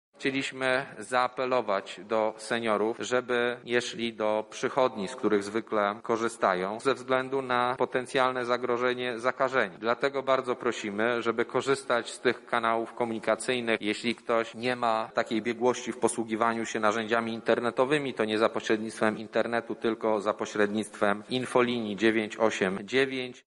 -mówi Szef Kancelarii Prezesa Rady Ministrów Michał Dworczyk.